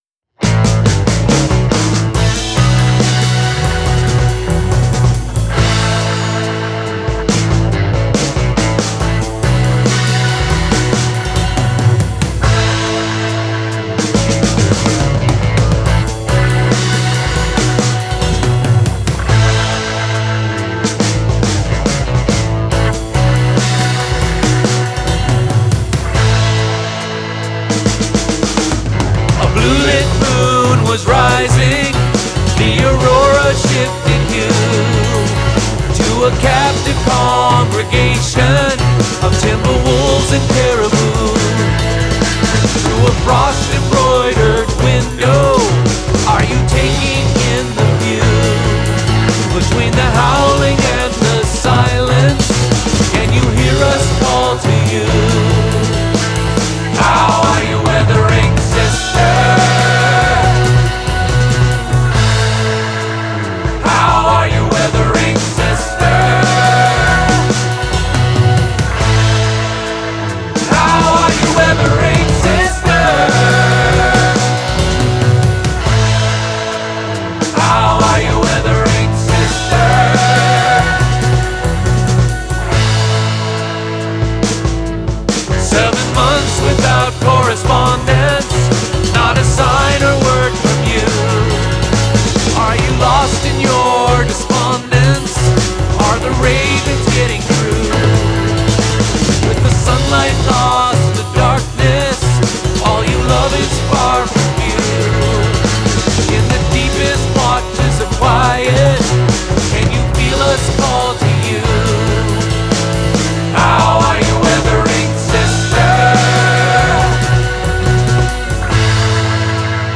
Not sure what genre this is but I’m calling it ‘Stoner Prog’. I had a lot of fun running my Baritone telecaster through our Studio Leslie on this one and I’m not sure what it’s fate will be so I’m posting it here for now and maybe it will see further work or get on an album later although ‘releasing’ anything seems increasingly absurd these days.
What mixing has been done so far was done in Mixbus 10 Pro which is kind of new for me, I was pretty pleased with the channel strips and I only went off the reservation for Reverb (Fabfilter Pro-R2) and U-he Presswerk on the Master because I can find what I want with it in seconds.
A great blend of old school classics and modern sound.
So with the drums done, I had been working on a different song using our Leslie rotating speaker with my guitar and I thought that sound might also suit this song so I tracked the main guitar with the upper Leslie rotor mic’d in Stereo for the quasi doppler effect and the lower rotating drum in mono. I added 2 more regular guitars through a Fender Vibrolux amp mic’d with a Sennheiser e609, an old Boss Turbo OD pedal was used to drive the amp.
The vocals were done last and I kinda made things up as I went along, I liked the idea of double tracking the Verses an octave lower than the main melody so I did that and some harmonies in the Choruses and Bridge and the Vocals were also done through the Baby Bottle.
There’s just something about that snare that screams “programmed drums” to me.